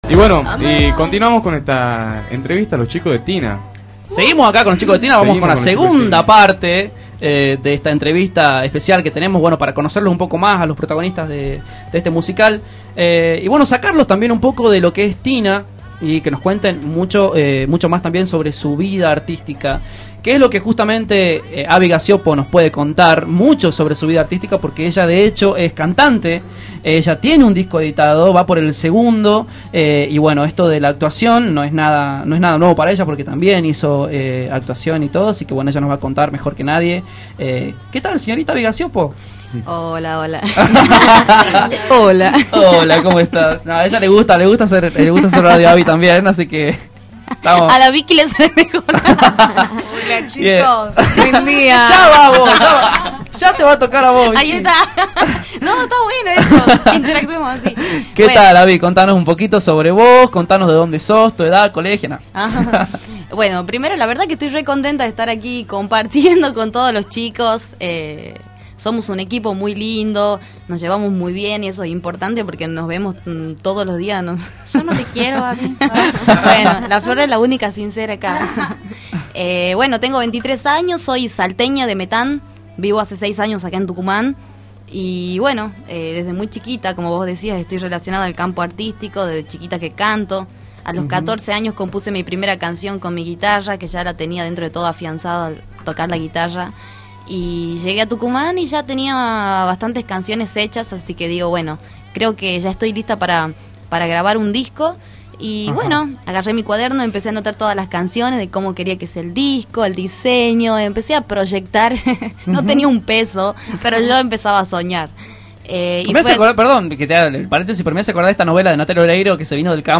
El elenco de la obra teatral visitó los estudios de Radio Q y contó la experiencia vivida en el año del bicentenario de la Declaración de la Independencia al equipo de Los Zeta Generación